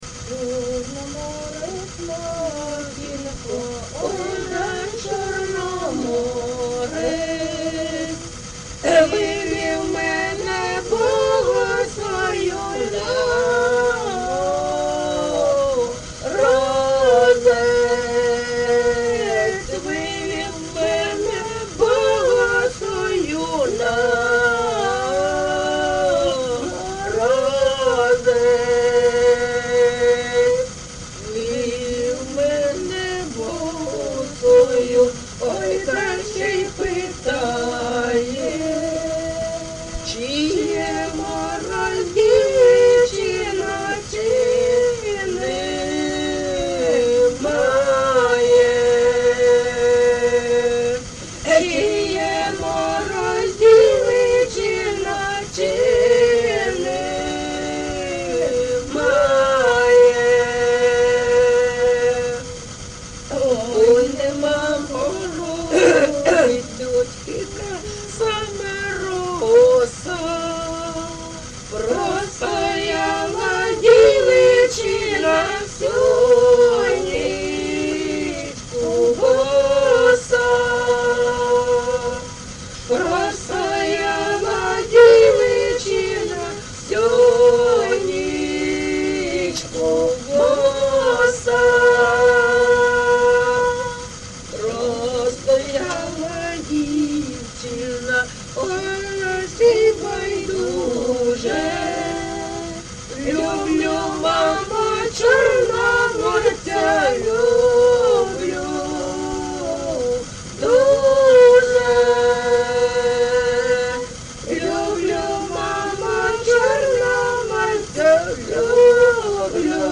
ЖанрПісні з особистого та родинного життя
Місце записус. Рідкодуб, Краснолиманський (Лиманський) район, Донецька обл., Україна, Слобожанщина